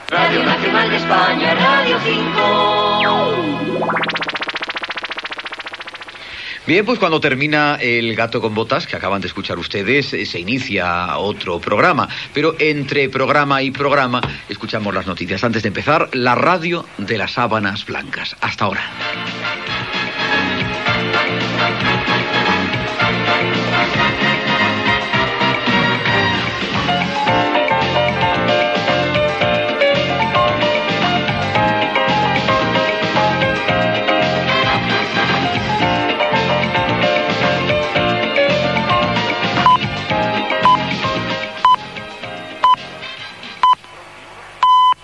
Indicatiu Radio 5 de RNE José Manuel Parada dona pas a les notícies abans de l'inici de "La radio de las sábanas blancas"